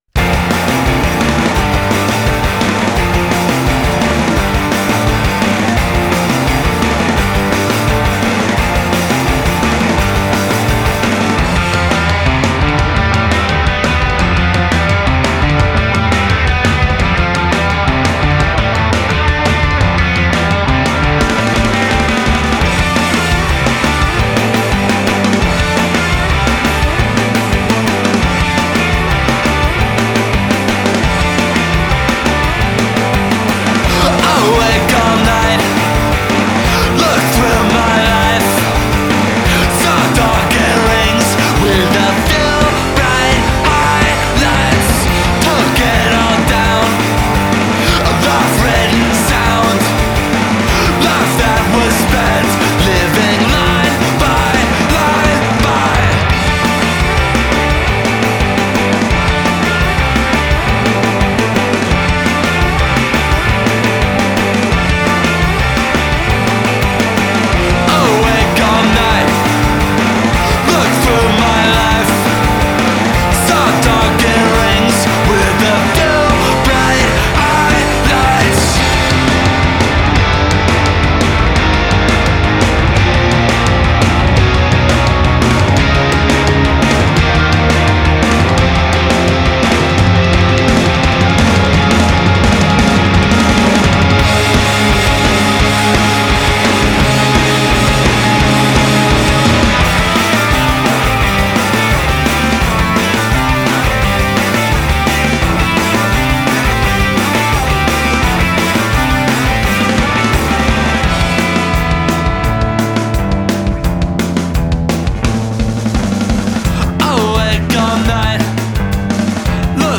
throaty vocal rage